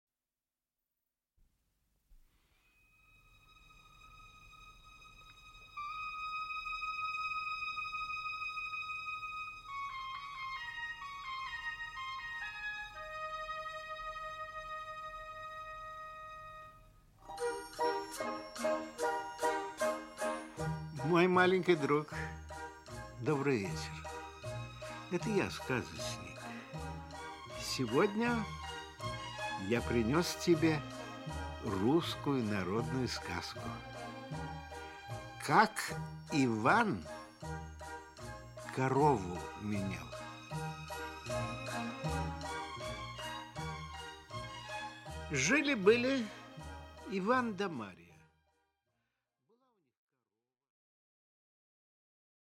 Аудиокнига Как Иван корову менял | Библиотека аудиокниг
Aудиокнига Как Иван корову менял Автор Народное творчество Читает аудиокнигу Николай Литвинов.